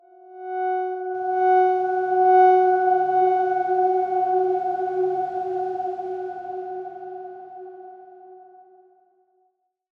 X_Darkswarm-F#4-mf.wav